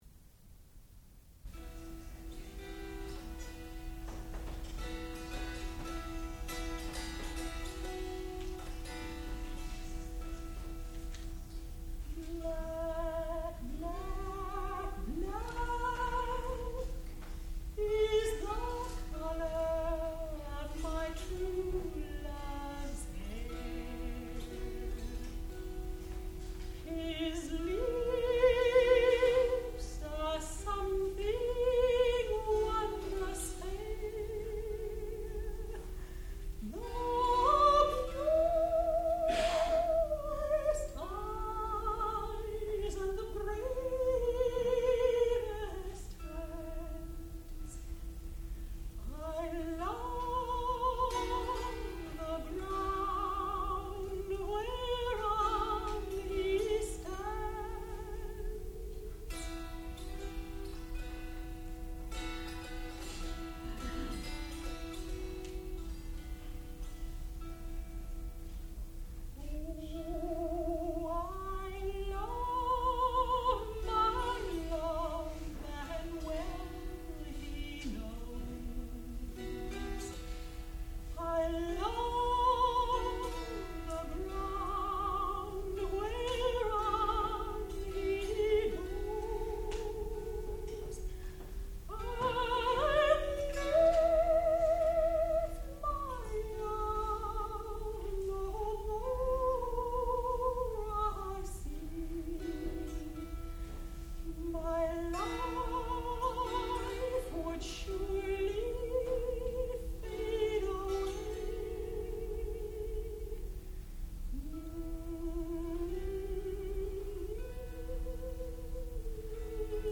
American folk songs
dulcimer, guitar and banjo
soprano